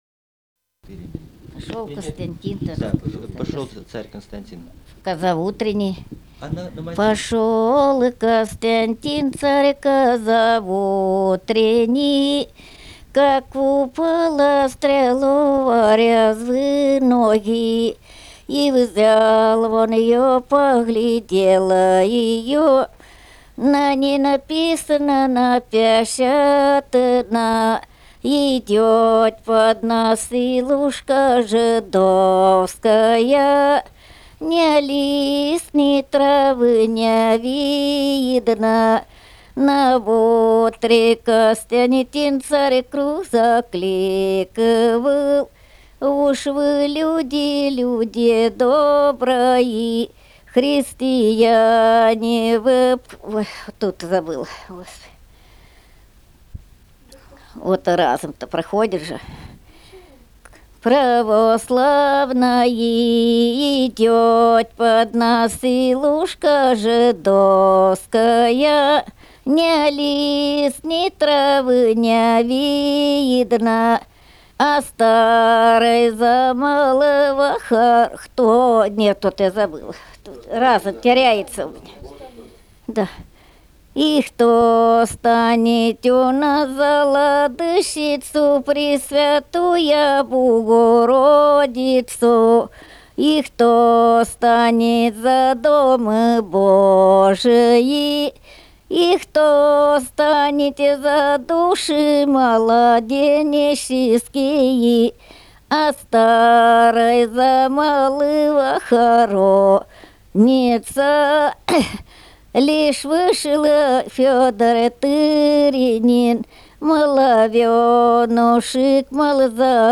Собрание имеет звуковое приложение – диски с записью аутентичного исполнения былинных песен и духовных стихов самими носителями эпических традиций.